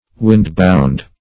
Search Result for " windbound" : The Collaborative International Dictionary of English v.0.48: Windbound \Wind"bound`\, a. (Naut.) prevented from sailing, by a contrary wind.